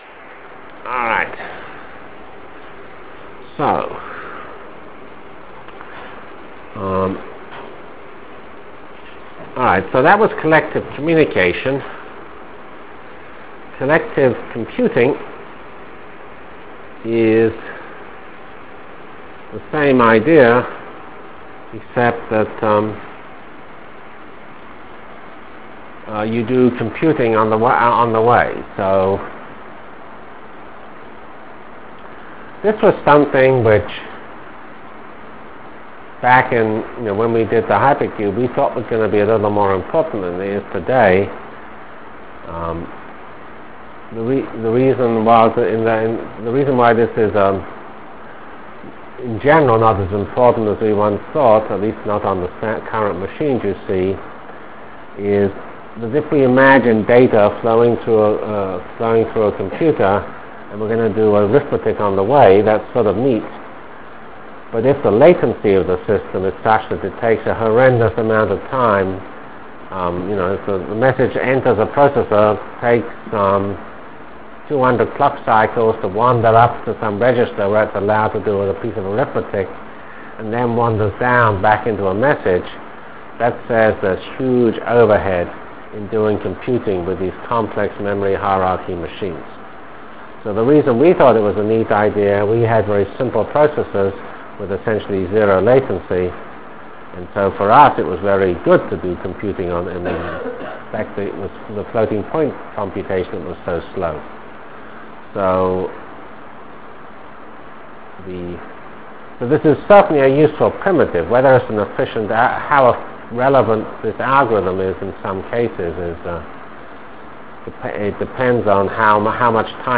Lecture of November 7 - 1996